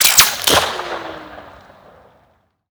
Railgun_Far_03.ogg